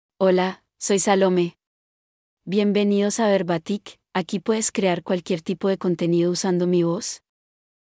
FemaleSpanish (Colombia)
Salome — Female Spanish AI voice
Salome is a female AI voice for Spanish (Colombia).
Voice sample
Listen to Salome's female Spanish voice.
Salome delivers clear pronunciation with authentic Colombia Spanish intonation, making your content sound professionally produced.